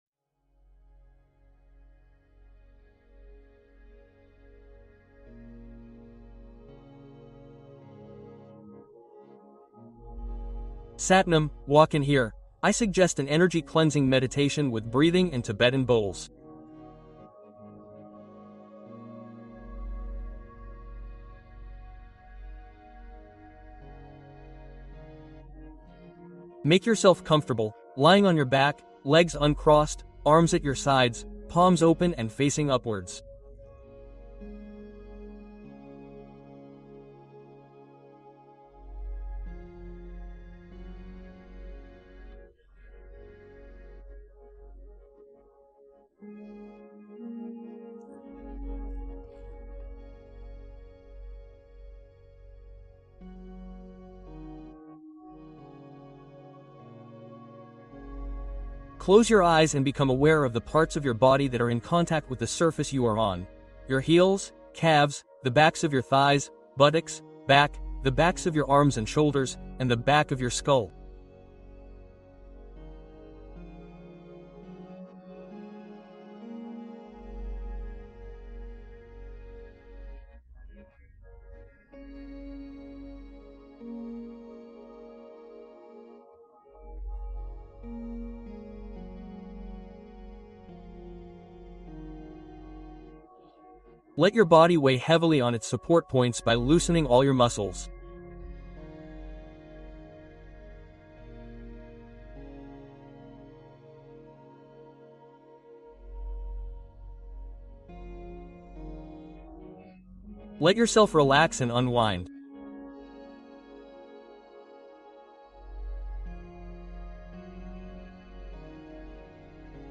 Bols Tibétains : Le nettoyage énergétique qui dissout anxiété, peurs et stress instantanément